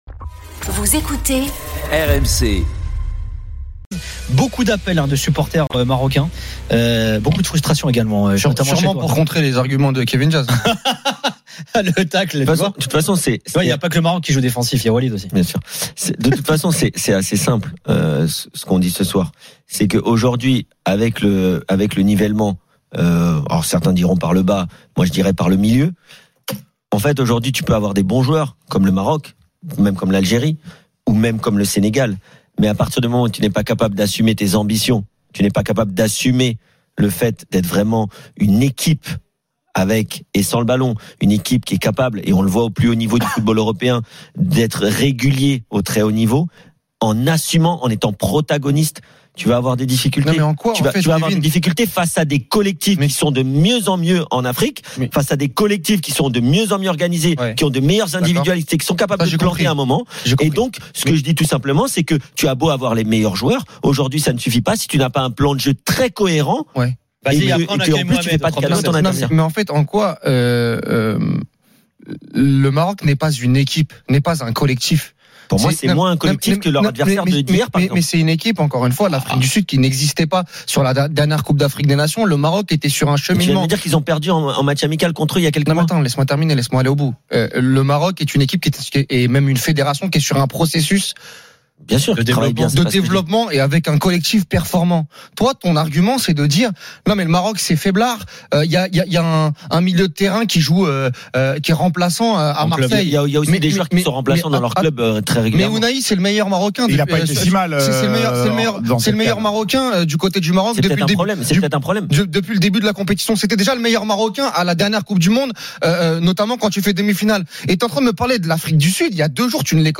Le Top de Génération After : Débat enflammé suite à l'élimination du Maroc à la CAN ! – 31/01
Chaque jour, écoutez le Best-of de l'Afterfoot, sur RMC la radio du Sport !